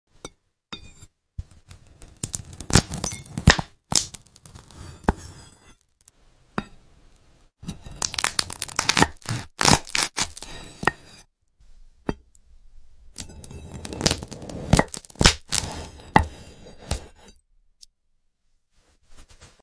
Glass sushi cutting ASMR🍱🍣 Ai sound effects free download